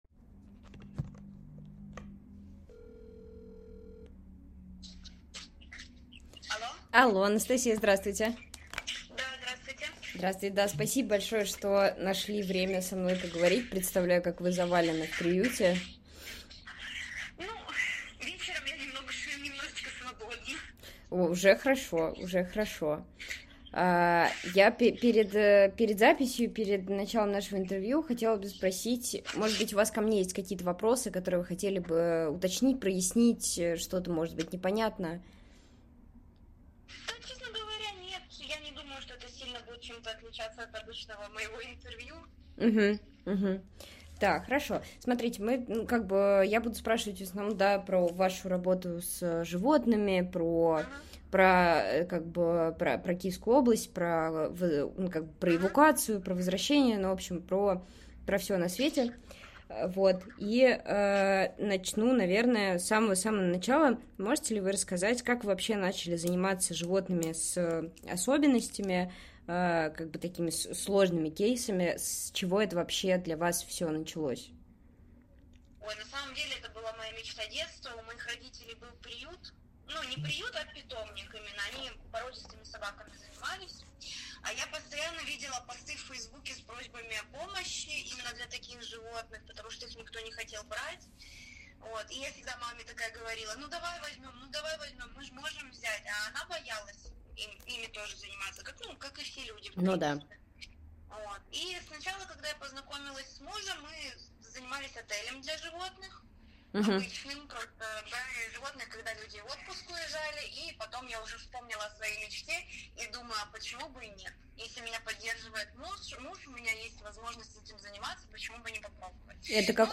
Волонтерка из Ирпеня рассказывает, как во время оккупации спасала и эвакуировала животных. Пешком с мужем они вывели десятки собак и кошек, прятались с ними под обстрелами, а после освобождения города вернулись и продолжили помогать.
Интервью Предыдущая Следующая Часть 1 / 2 Скачать аудио -10 +10 1 1,5 2 Все части Часть 1 NaN:NaN:NaN Часть 2 NaN:NaN:NaN Расшифровка Расшифровка Скопировать расшифровку 00:06 Алло.